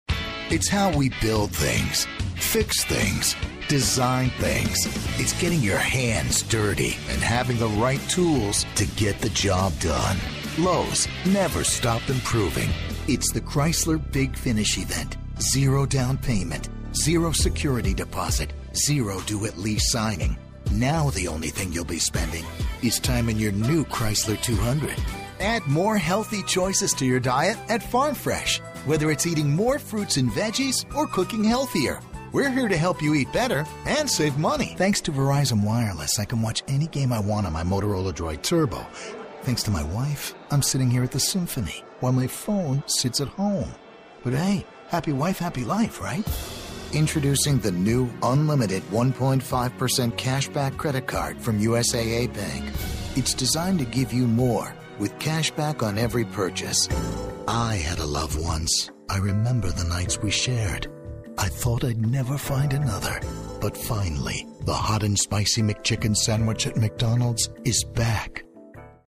compelling, dynamic, velvety, contemplative, friendly, sexy, romantic, buttery, adaptable and easy to work with
englisch (us)
Sprechprobe: Werbung (Muttersprache):